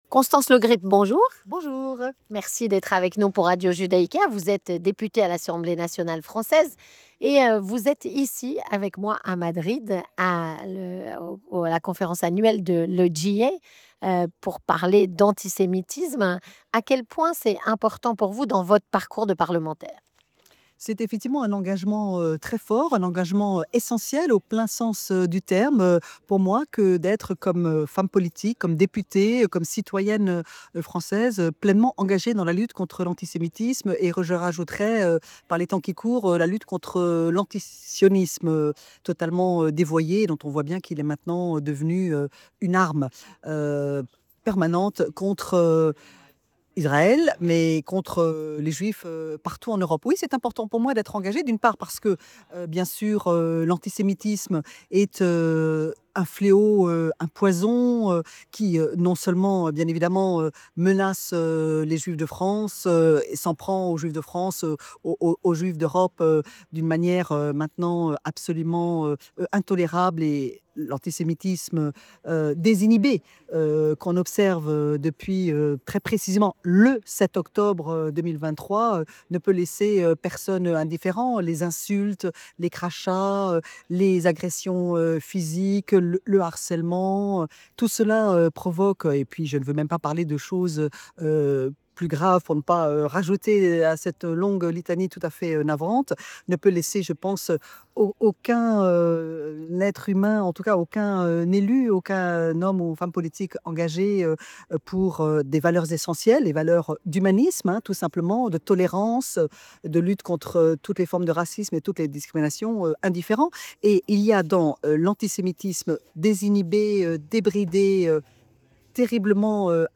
Constance Le Grip est députée à l’assemblée nationale francaise.